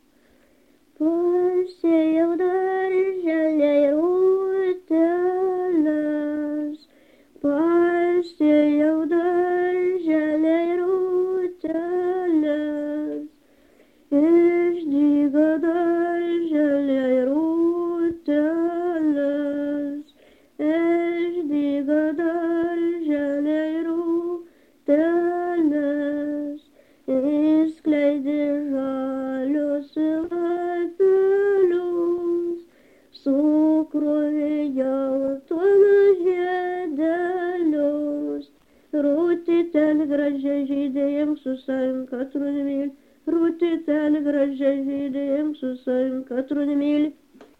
Subject daina
Erdvinė aprėptis Struikai
Atlikimo pubūdis vokalinis